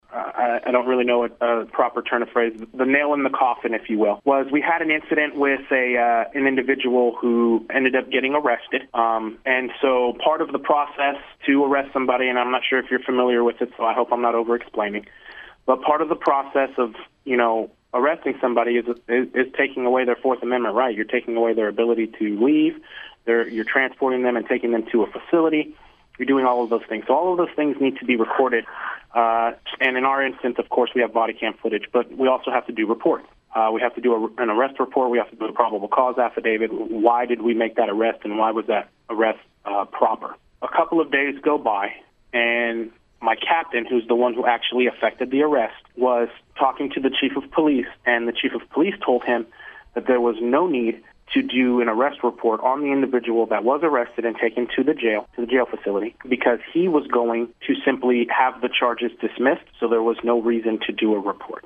Our sister station in Coffeyville was able to speak with one of the officers who resigned, who will remain anonymous at this time.